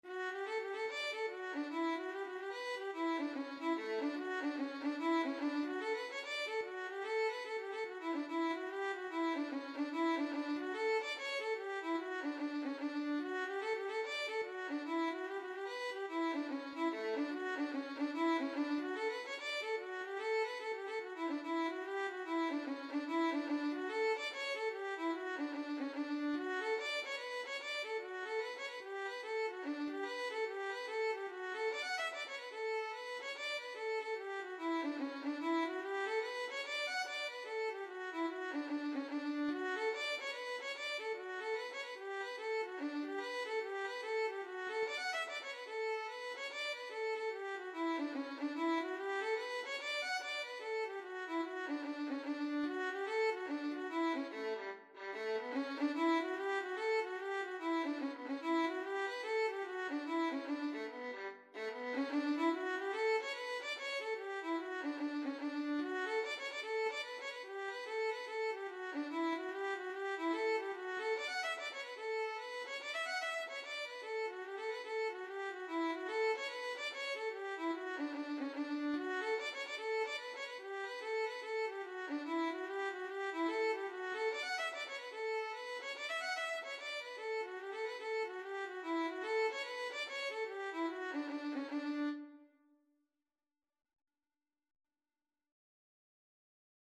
Free Sheet music for Violin
G major (Sounding Pitch) (View more G major Music for Violin )
2/2 (View more 2/2 Music)
B4-B6
Instrument:
Hornpipes
Irish